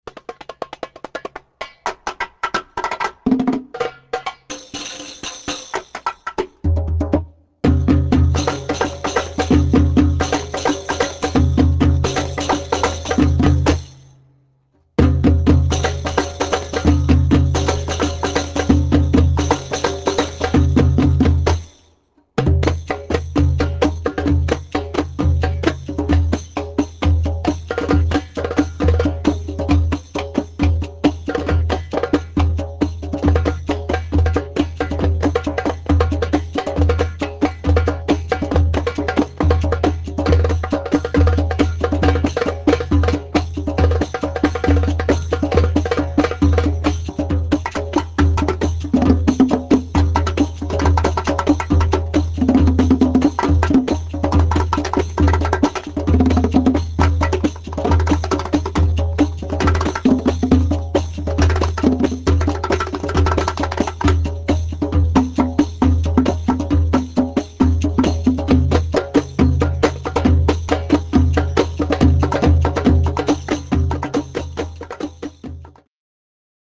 doumbec and tambourine